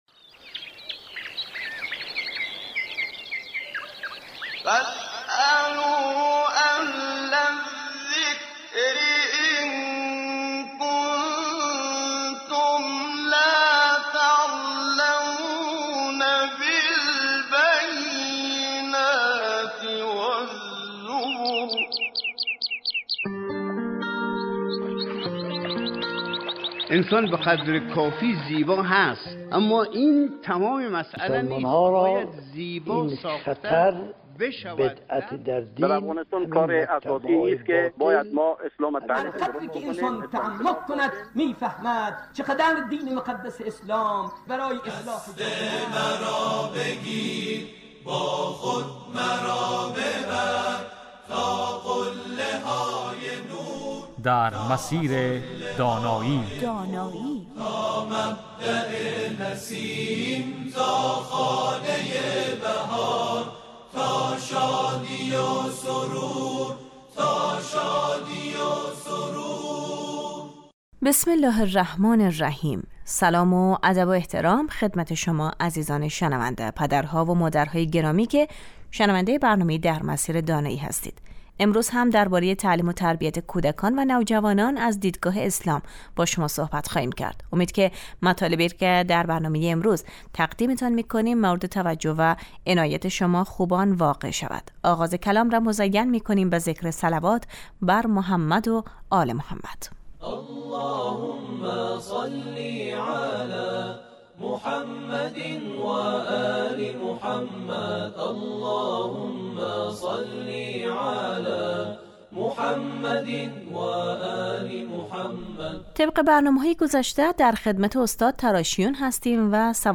این برنامه 20 دقیقه ای هر روز بجز جمعه ها ساعت 11:35 از رادیو دری پخش می شود